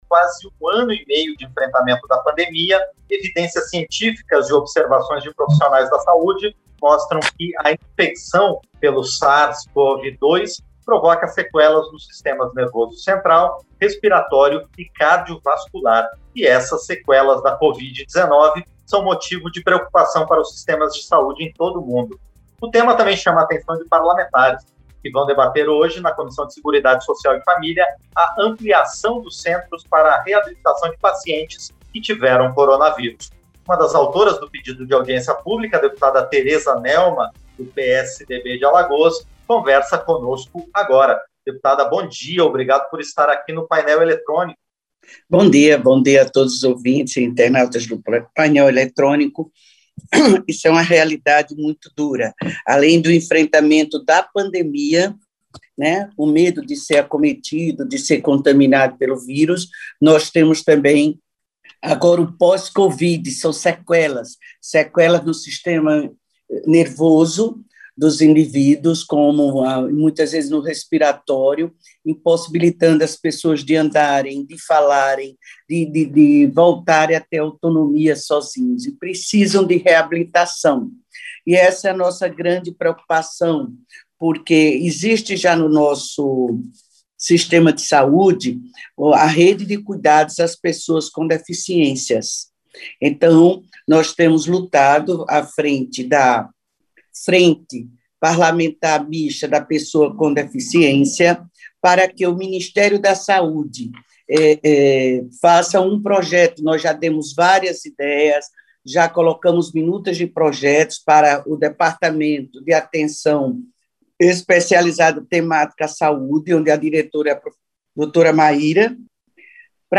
Entrevista – Dep. Tereza Nelma (PSDB-AL)